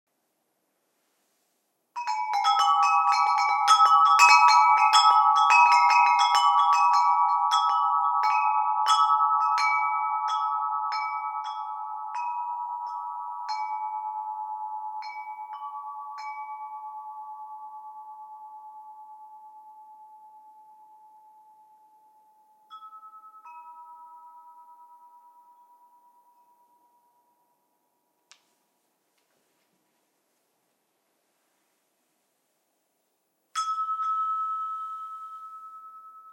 These chimes are characterized by a particularly good resonance and long reverberation. They are tuned on the base A4/a' 432 Hz. Enjoy the soft and soothing sound.